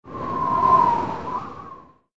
SZ_TB_wind_2.ogg